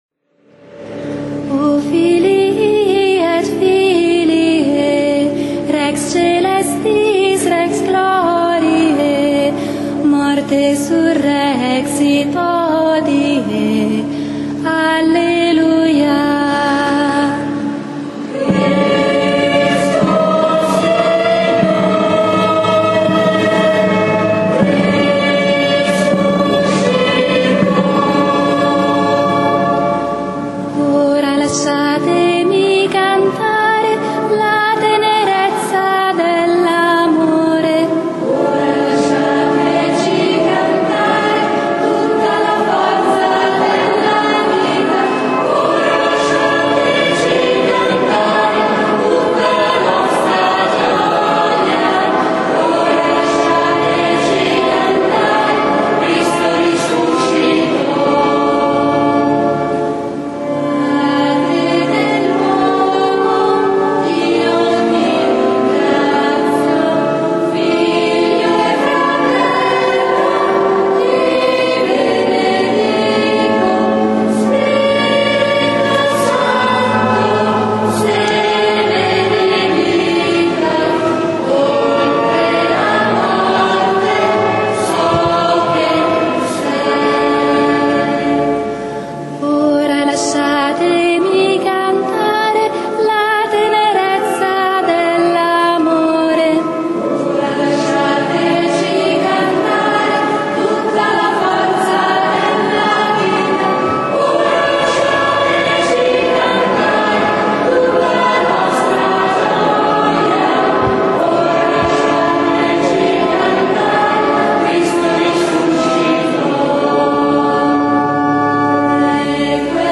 SABATO SANTO -Celebrazione della Resurrezione del Signore-
canti: